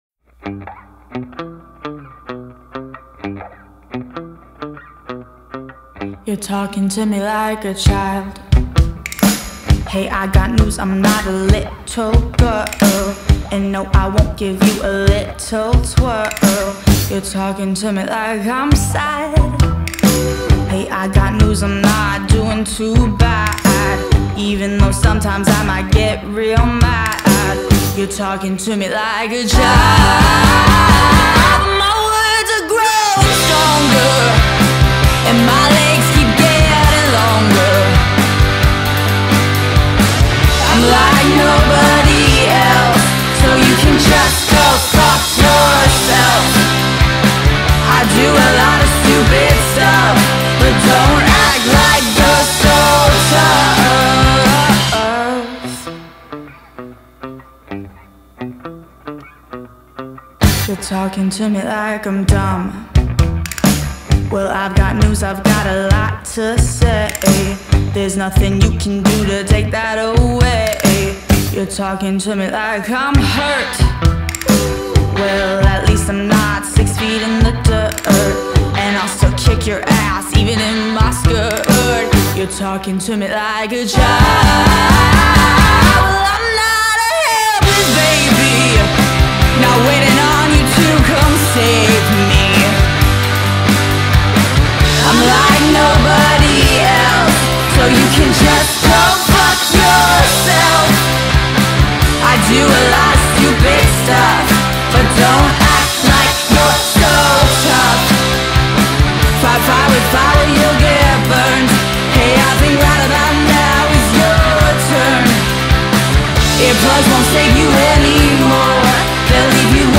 seashore.mp3